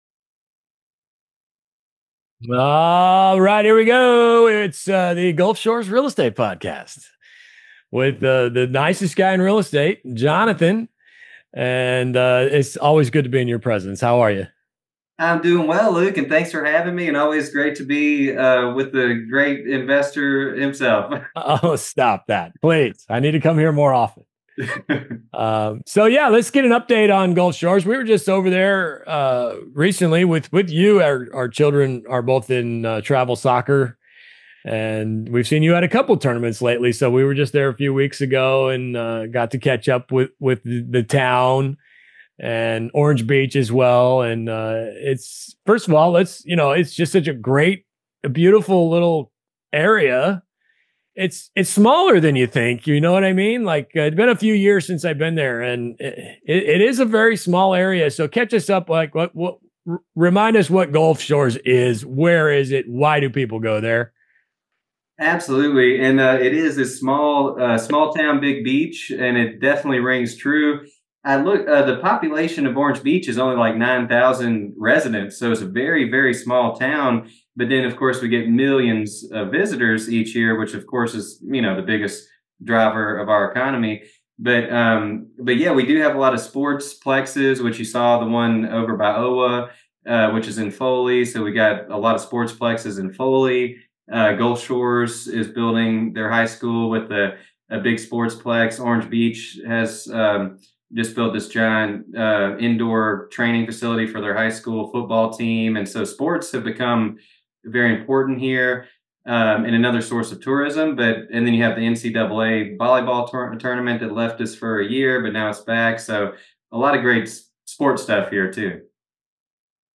The conversation also touches on tourism drivers like sports travel, infrastructure expansion, and local news that could impact future demand.